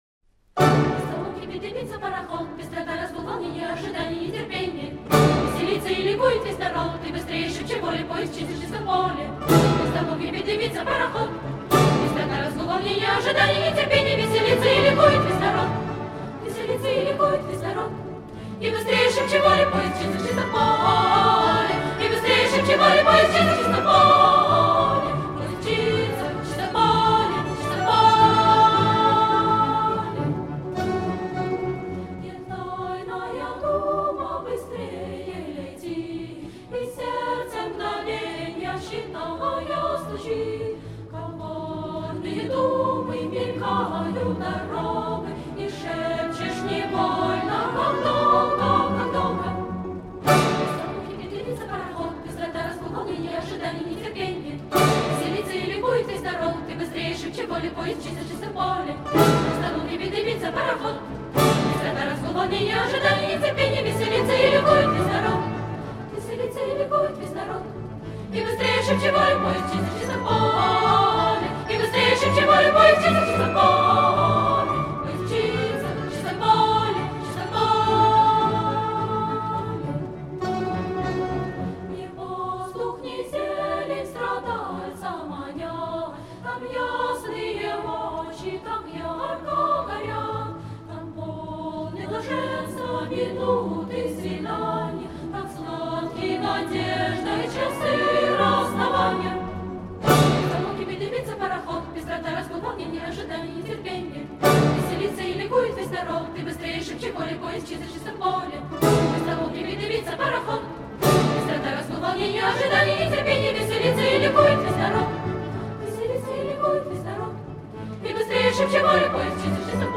в исполнении Большого детского хора